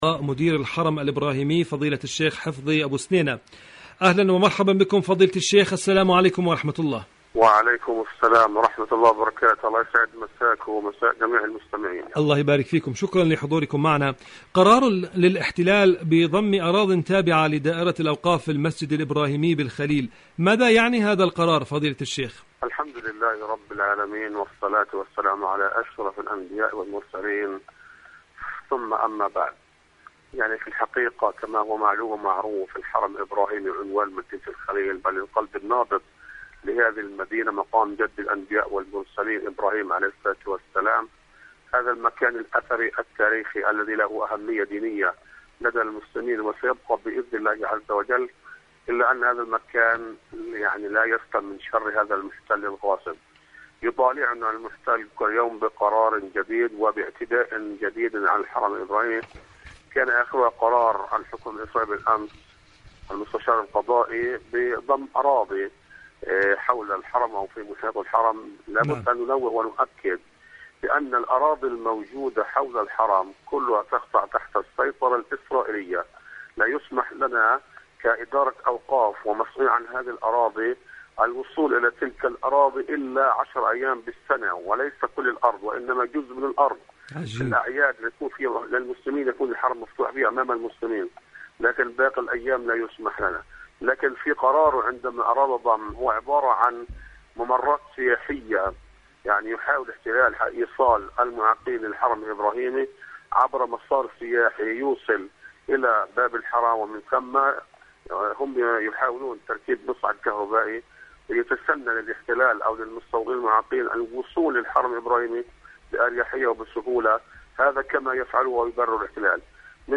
المسجد الإبراهيمي خط أحمر.. مقابلة